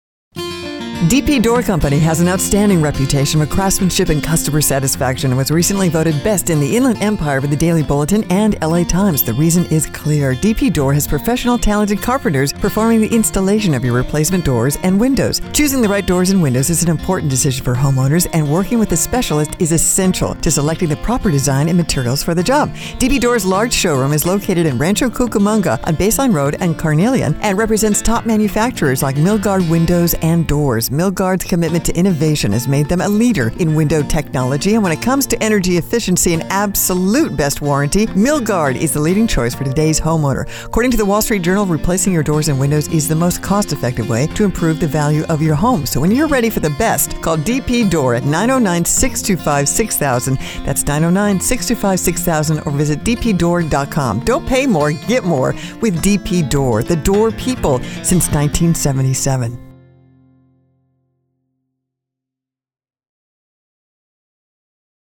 Our KLOS Radio spot.